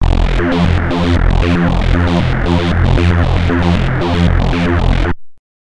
When LFO1 controls the pulsewidth of all three oscillators in addition, it results into following sound: